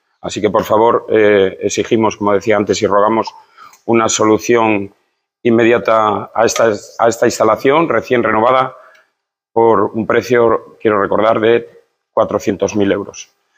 Así lo advirtió en el pleno de ayer el concejal regionalista Vicente Nieto, quien formuló un ruego oral al final de la sesión para exigir al equipo de Gobierno una "solución inmediata" para esta instalación deportiva "recién renovada" por 400.000 euros.
Audio Vicente Nieto en el pleno